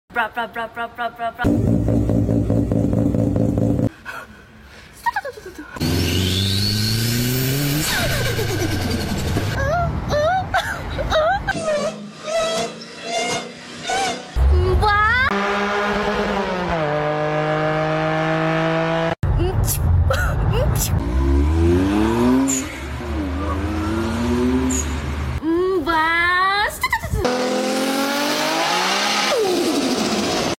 imitation of car exhaust sound effects